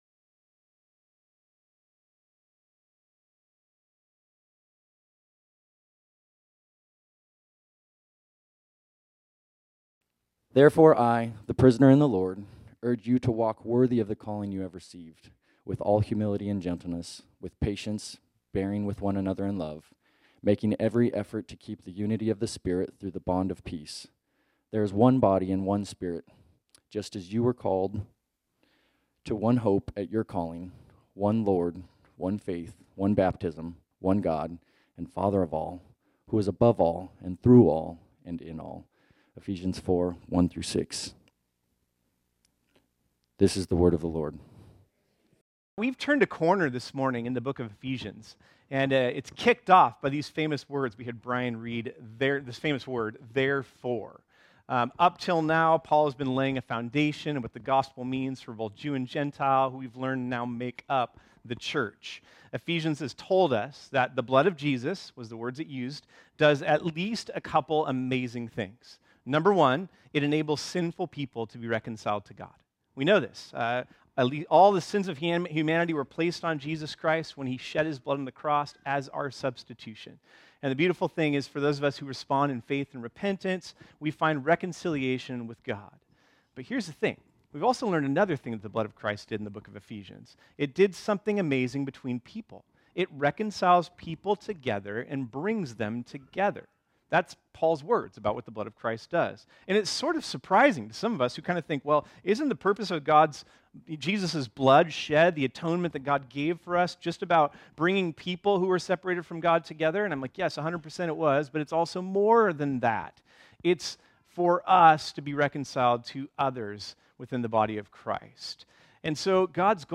Listen to preach on Ephesians 4.1-Ephesians 4.6 as part of our sermon series called Ephesians: New Life in Christ. This sermon was originally preached on Sunday, October 22, 2023.